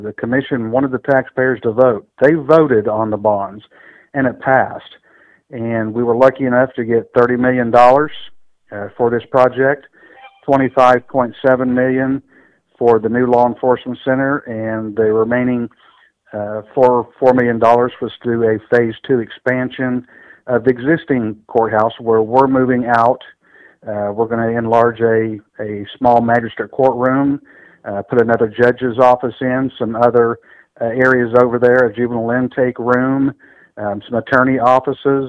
Voters approved the facility the following year. Johnson thanked voters for their foresight and support.